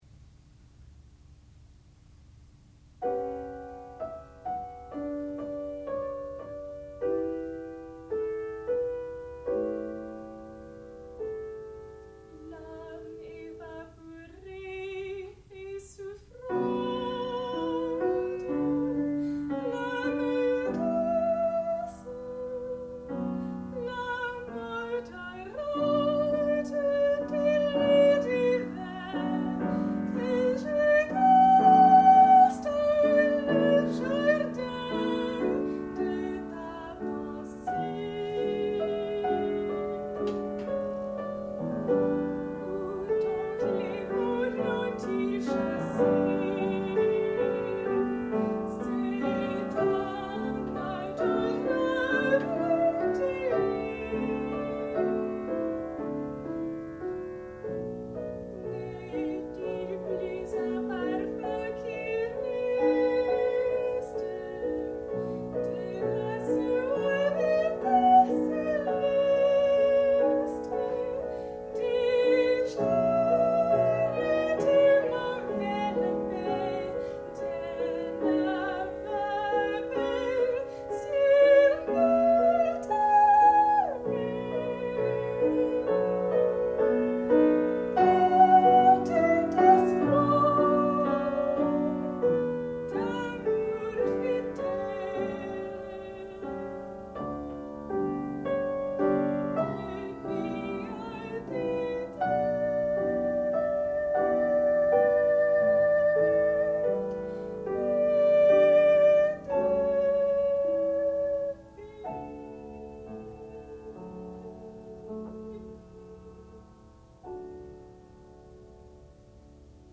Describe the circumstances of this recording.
Student Recital